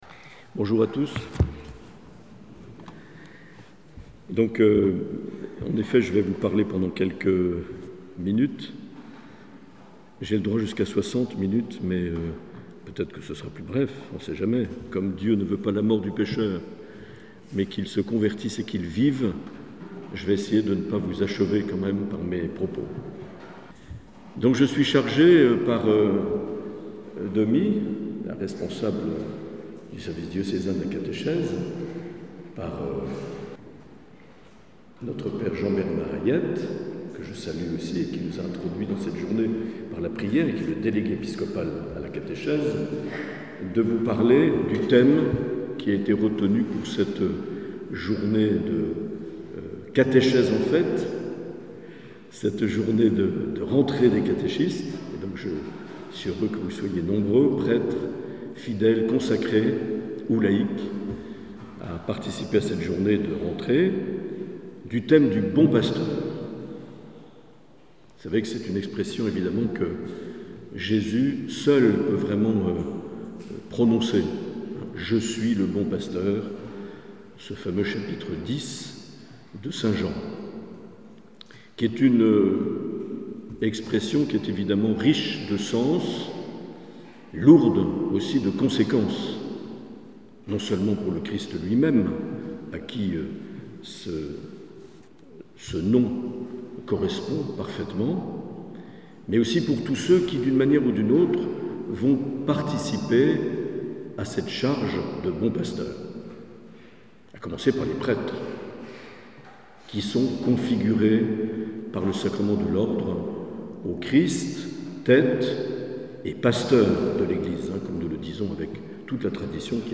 Conférence de Mgr Marc Aillet du 11 octobre 2022 à Salies-de-Béarn lors de la journée "Catéchèse en fête".